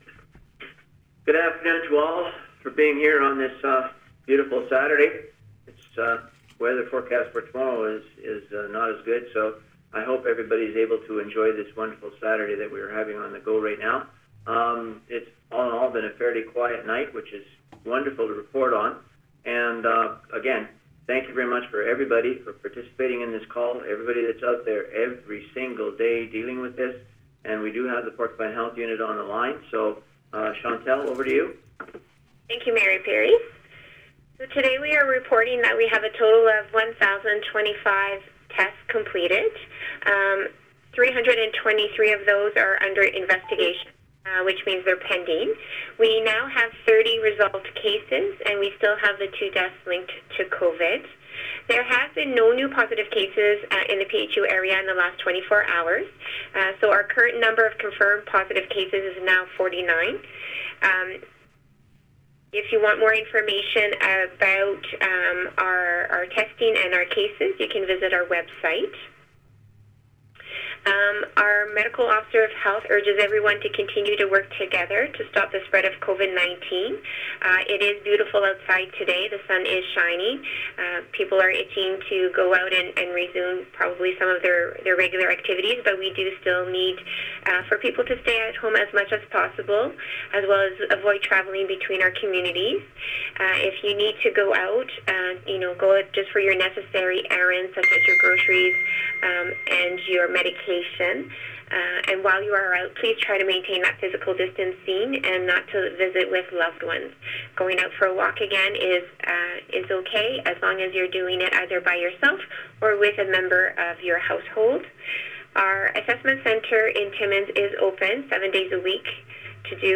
Here is the raw audio of today’s teleconference: